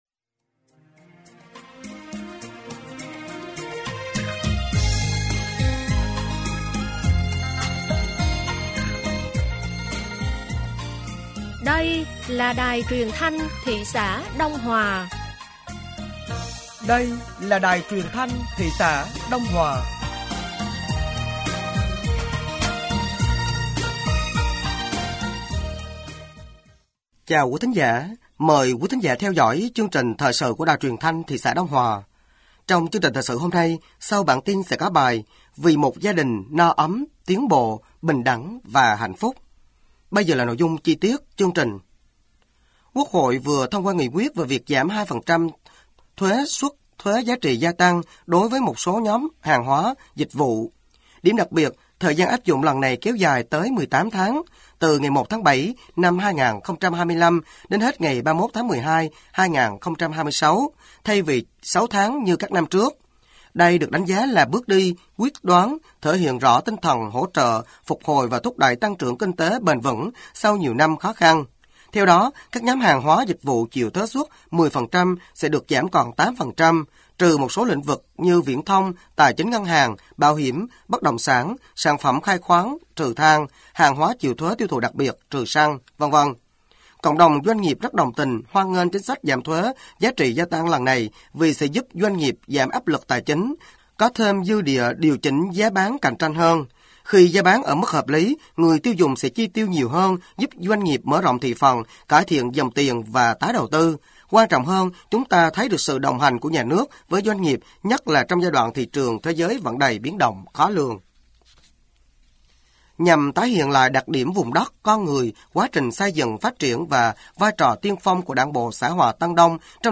Thời sự tối ngày 28/6/2025 sáng ngày 29/6/2025